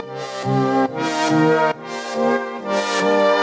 Index of /musicradar/french-house-chillout-samples/140bpm/Instruments
FHC_Pad A_140-C.wav